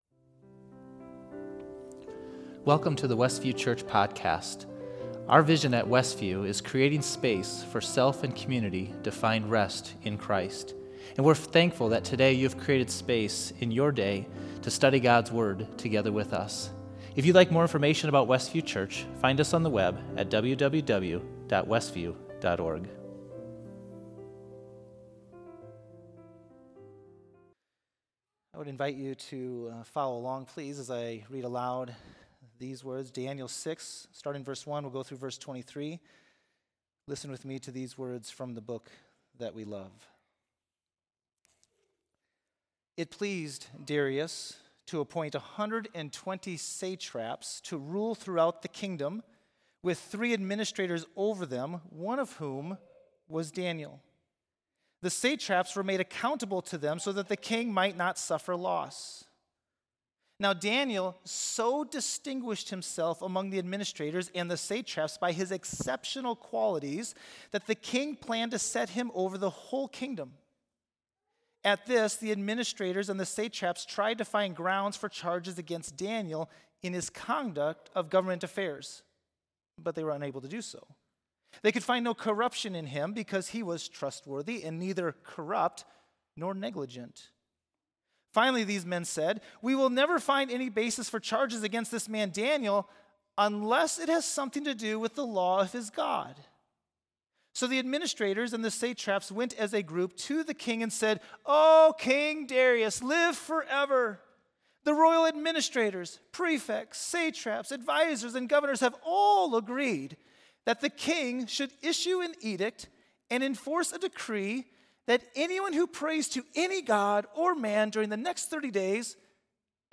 Facebook Livestream